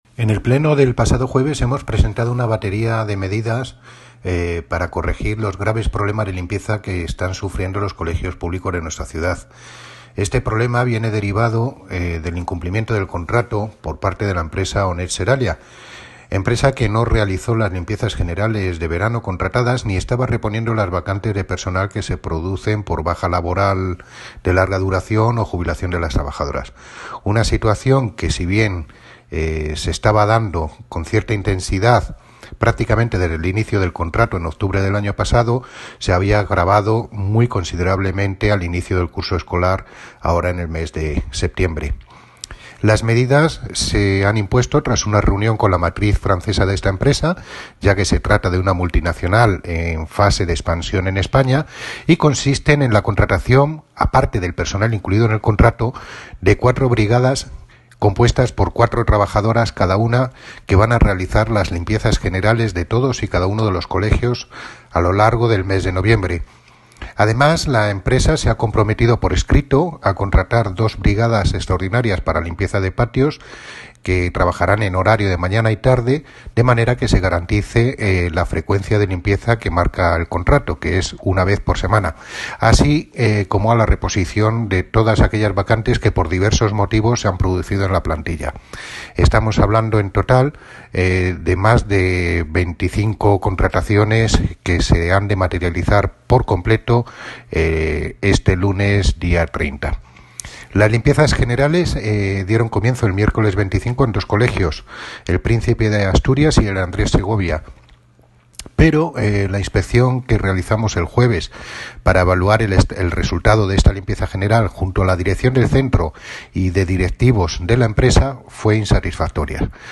Audio - Miguel Angel Ortega (Concejal de Medio Ambiente, Parques y Jardines y Limpieza Viaria) Sobre Plan Limpeza Colegios